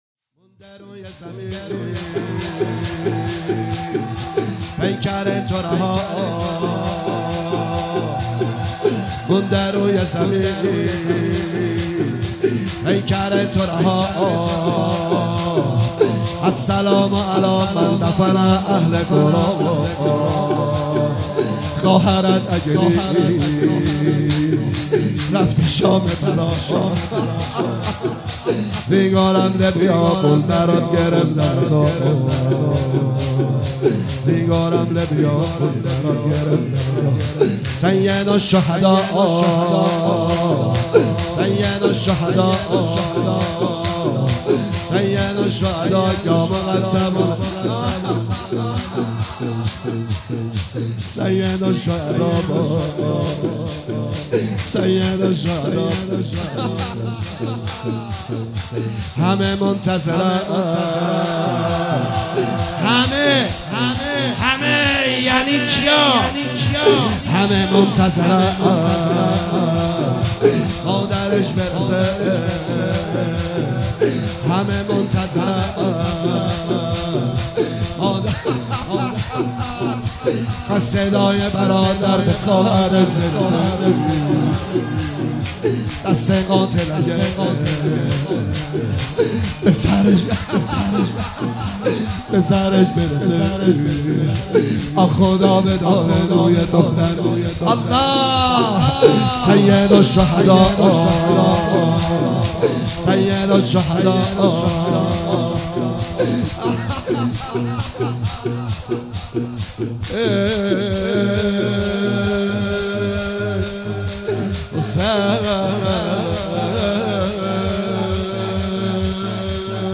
جلسات هفتگی هیئت رزمندگان مکتب الحسین (ع) با مداحی  نریمان پناهی